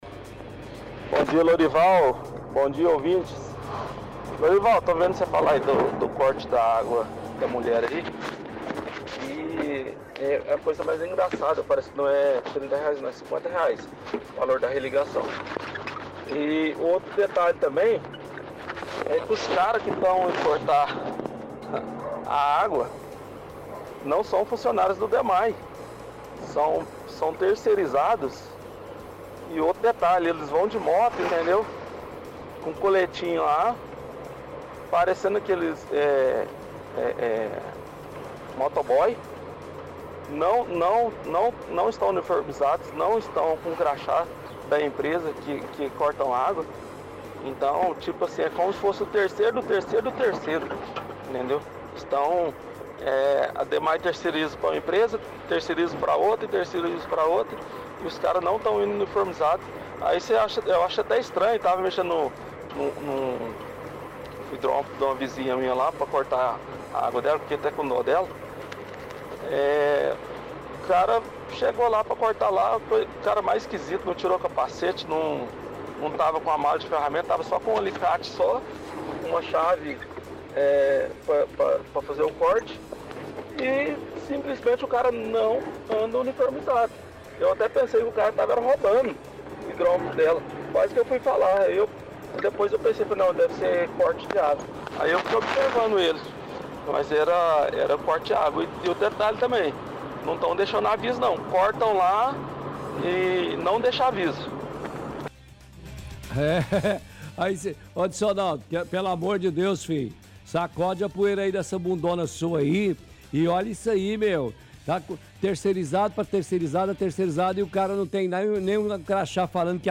– Ouvinte reclama que terceirizados do Dmae não estão uniformizados ao realizar serviços. Afirma também que não deixam nenhum aviso ao realizar cortes de água.